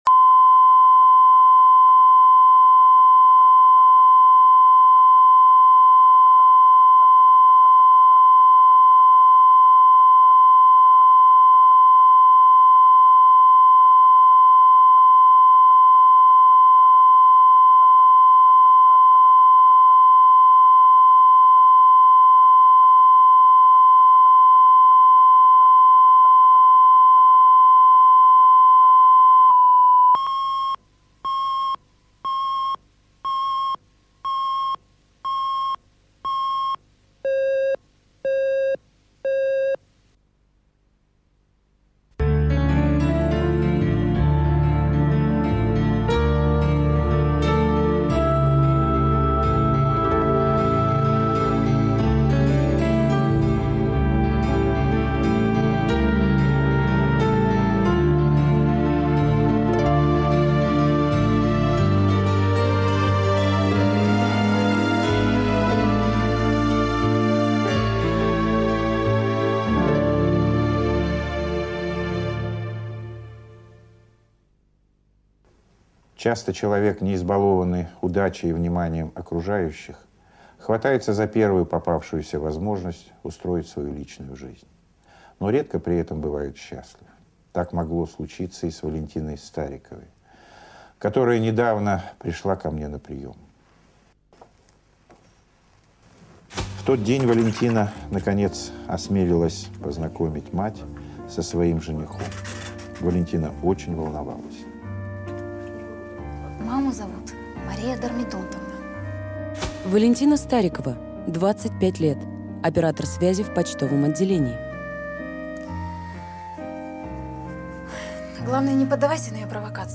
Аудиокнига Фальшивый жених | Библиотека аудиокниг